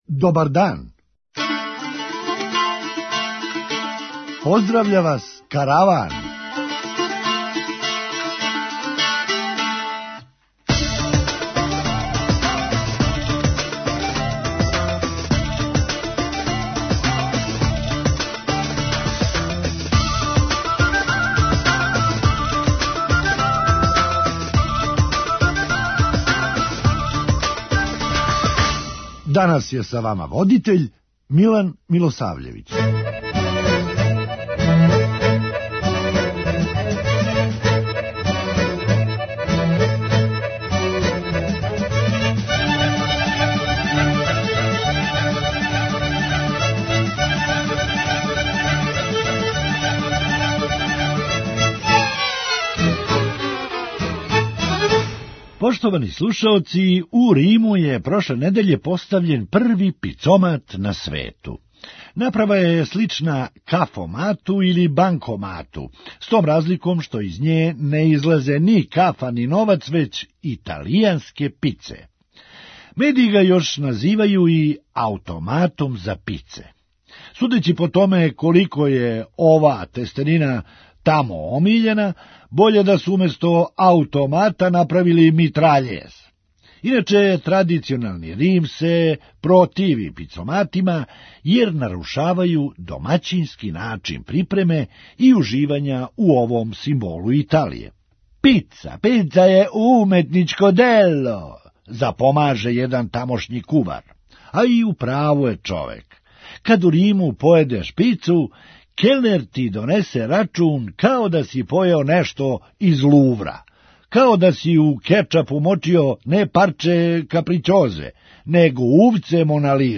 Хумористичка емисија
Још само да одглуми неког ко је обожавао цигарете и покрио је све пороке. преузми : 9.14 MB Караван Autor: Забавна редакција Радио Бeограда 1 Караван се креће ка својој дестинацији већ више од 50 година, увек добро натоварен актуелним хумором и изворним народним песмама.